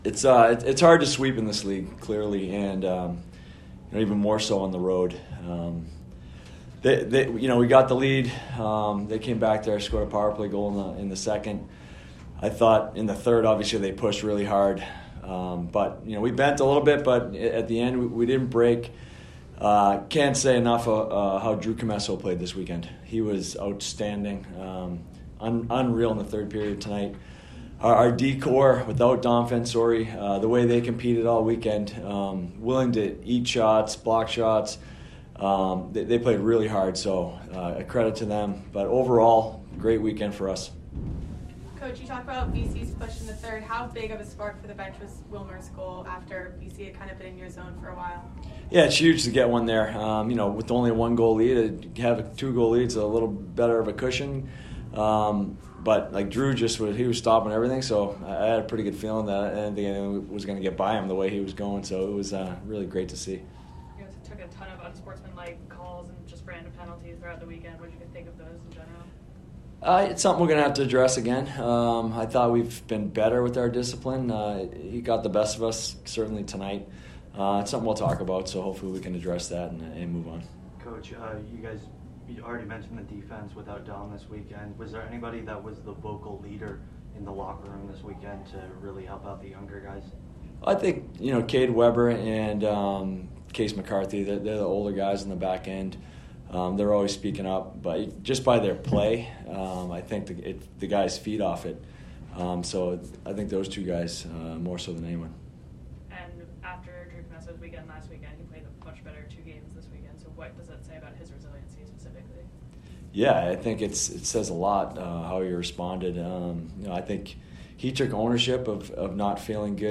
BC Postgame Interview